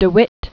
(də wĭt, vĭt), Jan 1625-1672.